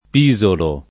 -ino — preval. in Tosc. la pn. sonora dell’ -s- , non senza frequenti incertezze dovute forse al confronto con Pisa